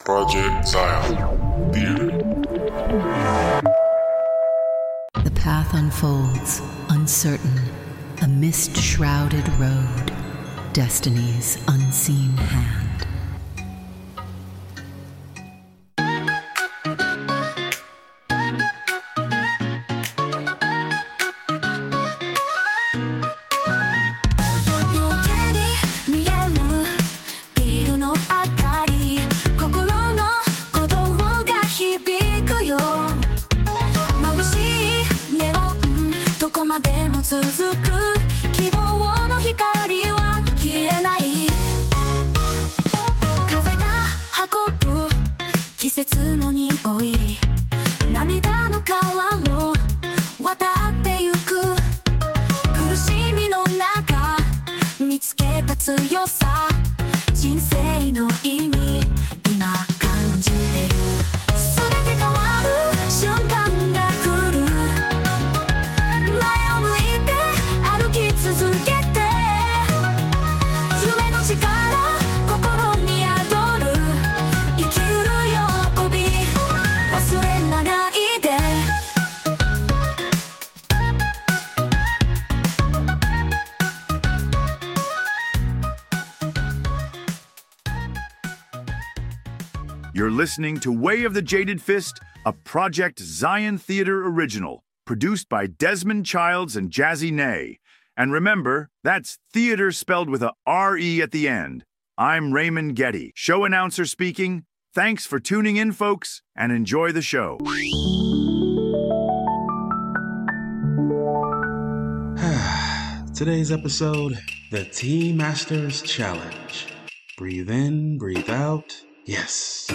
Other voices provided via text-to-speech. Voices used were implemented via text to speech or recorded remotely.
Series premiere of the anime inspired audio drama series.